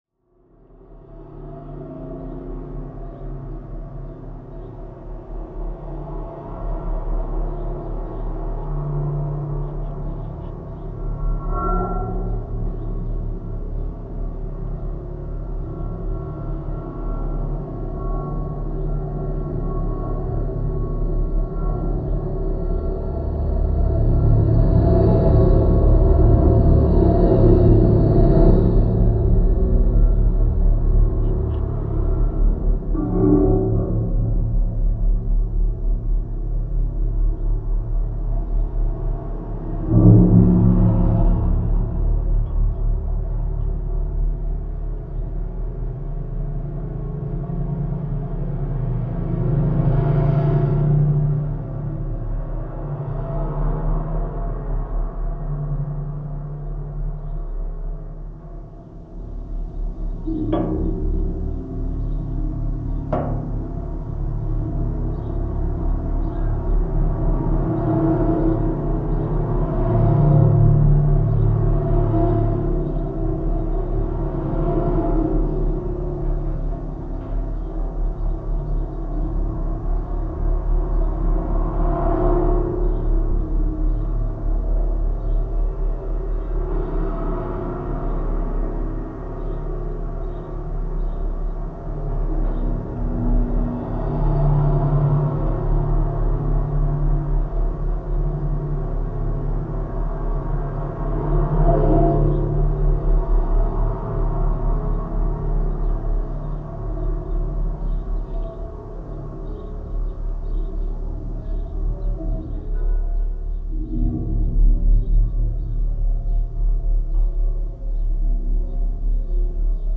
Equipment used: Zoom H6